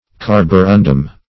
Carborundum \Car`bo*run"dum\ (k[aum]r`b[-o]*r[u^]n"d[u^]m), [a